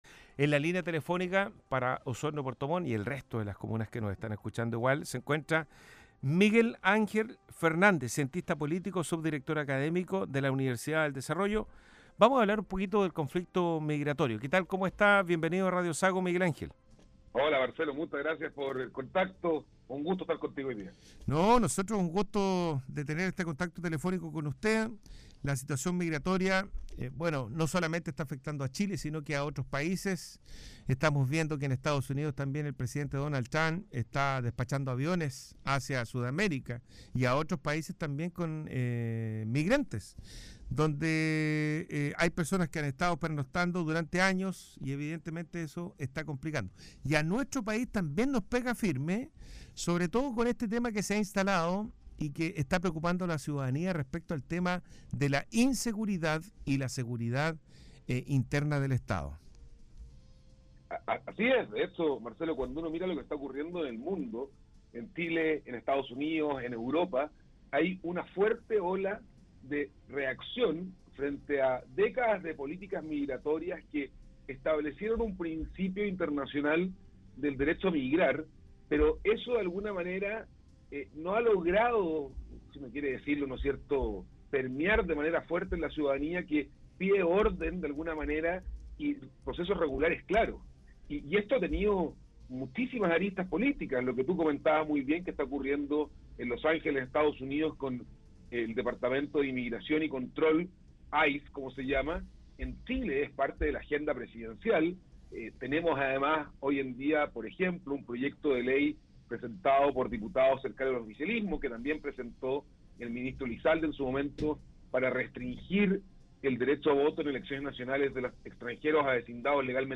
Escucha la Entrevista: Emigración - RadioSago